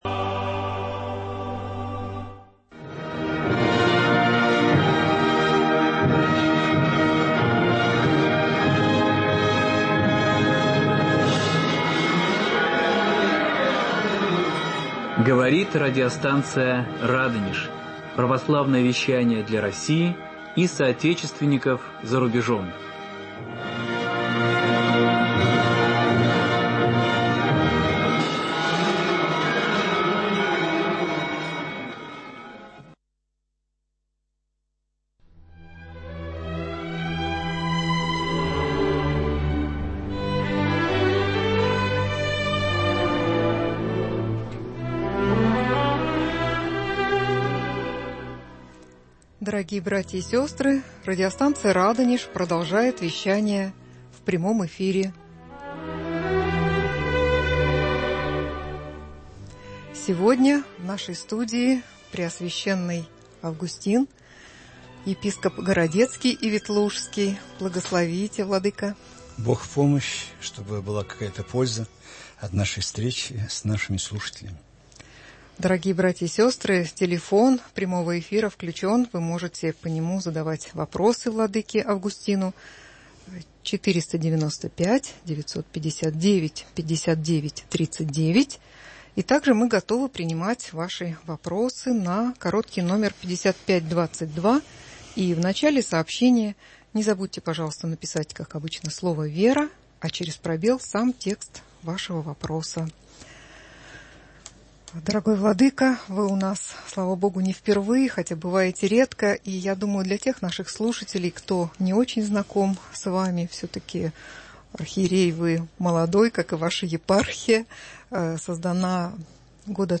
В прямом эфире епископ Августин (Анисимов) отвечает слушателям радио «Радонеж»